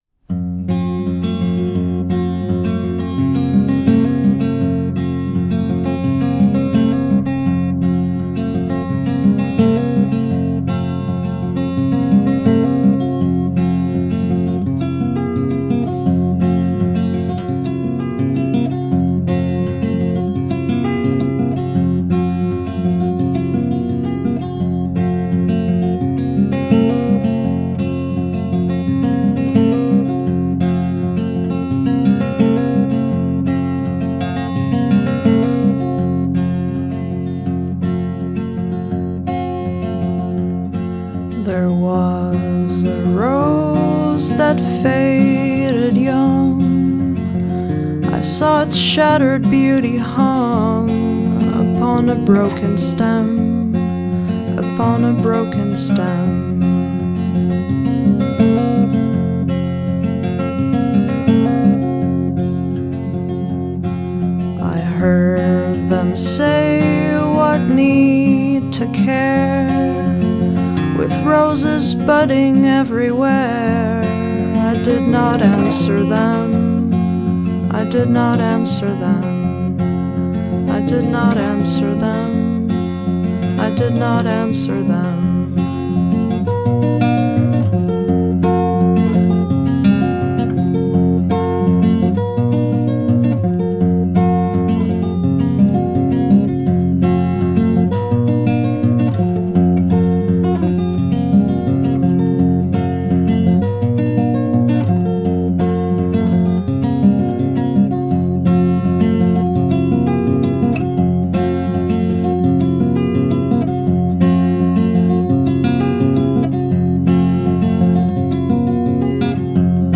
January 24, 2015 / / acoustic folk
folk ramblings
with beautifully cultivated guitar play